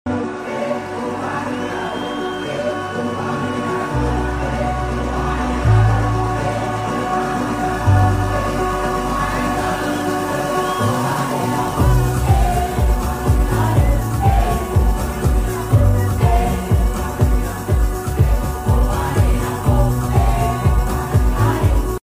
on stage for an unforgettable live set